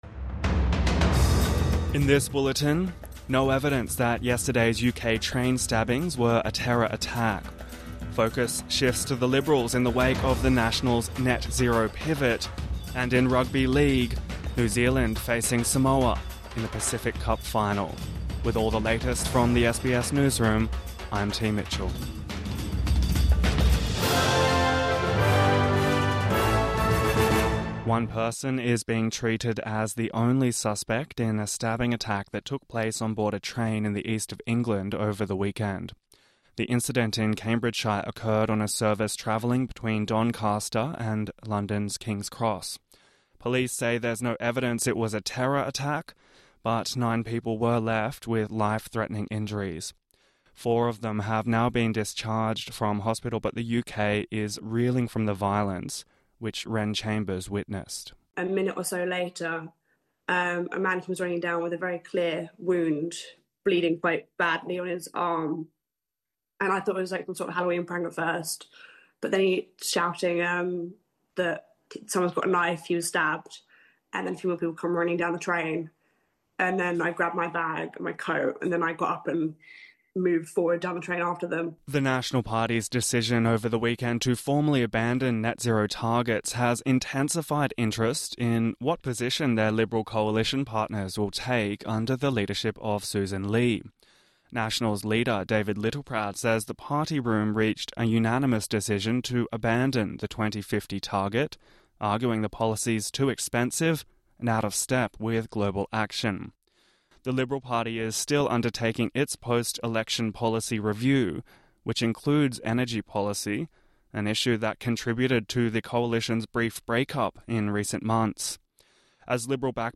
UK stabbing attack: four in life-threatening condition | Morning News Bulletin 3 November 2025